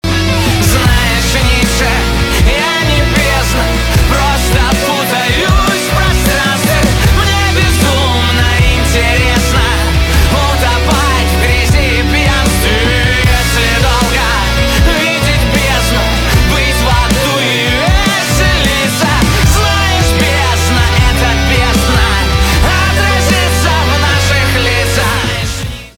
русский рок , гитара , барабаны , грустные
депрессивные